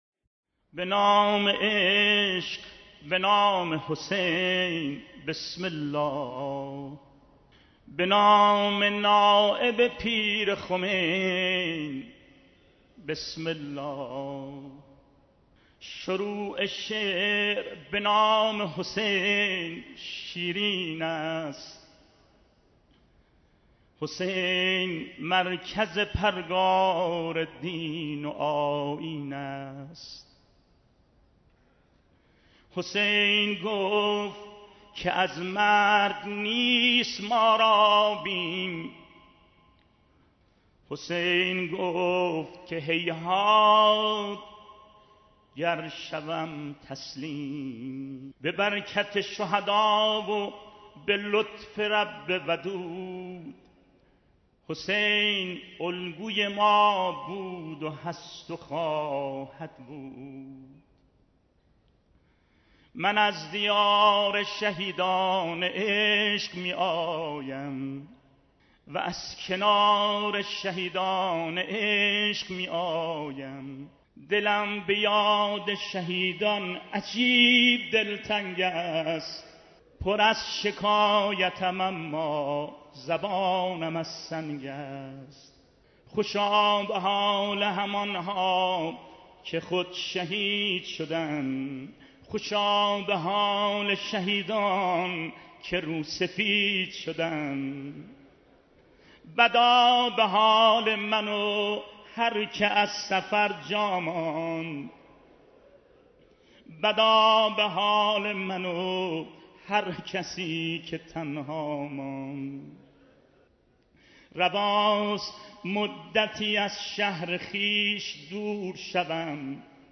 دیدار مسئولان، دست‌اندرکاران، راویان و خادمان راهیان نور
مداحی جناب آقای آهنگران